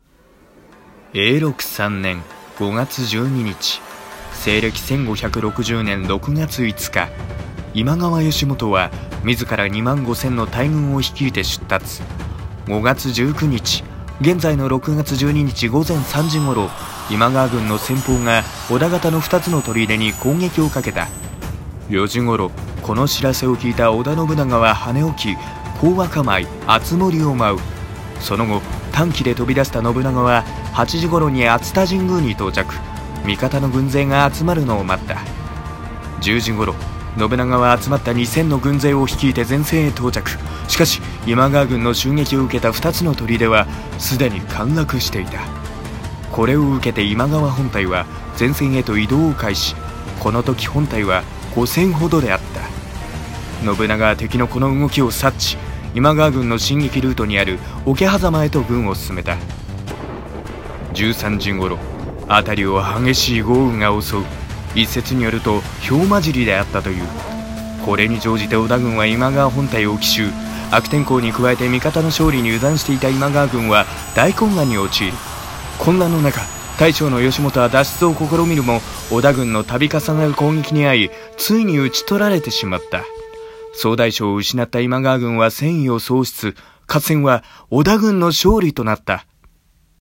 【ナレ台本】解説・桶狭間の戦い ナレーション